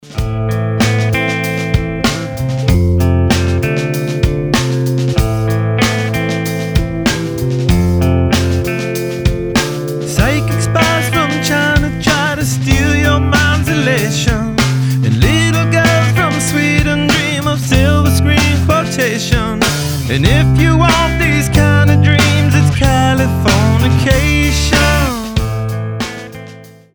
• Качество: 320, Stereo
гитара
мужской вокал
красивый мужской голос
спокойные
Alternative Rock
Funk Rock